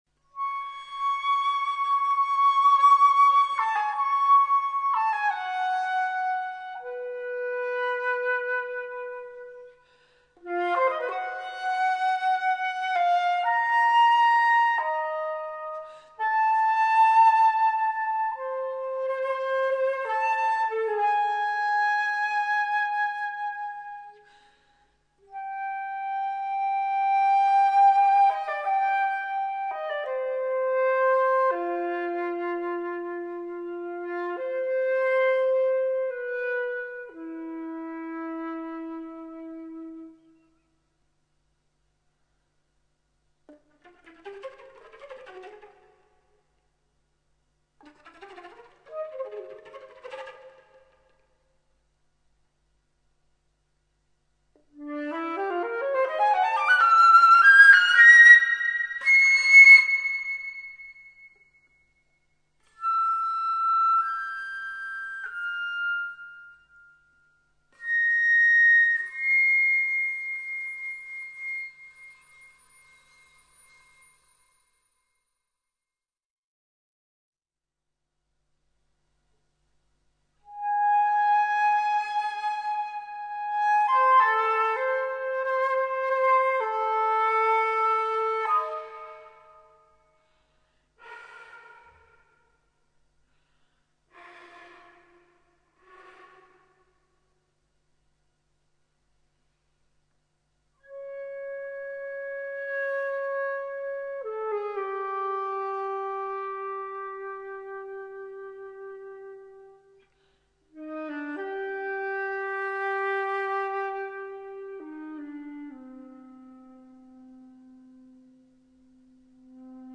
Lentamente rubato